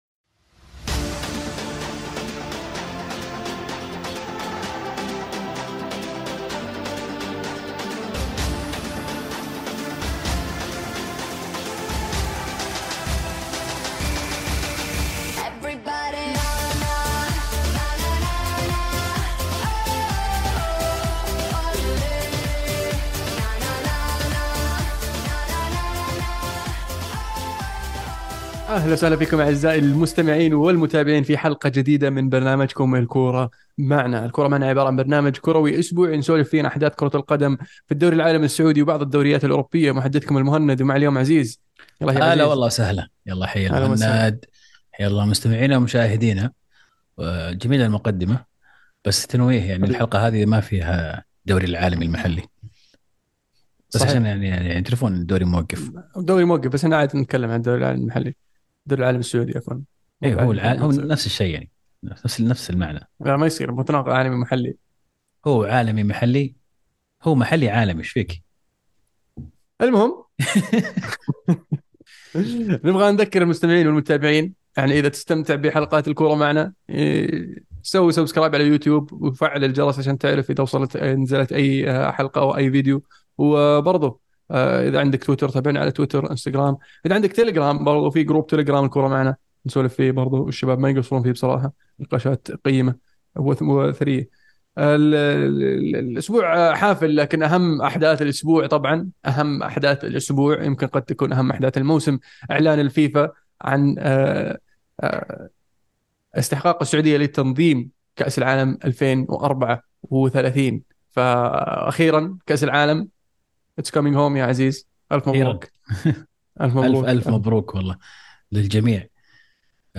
بودكاست "الكورة معنا" برنامج صوتي كروي اسبوعي من تقديم شباب عاشقين لكرة القدم، يناقشون فيه اهم الاحداث الكروية العالمية والمحلية خلال الأسبوع بعيد عن الرسمية.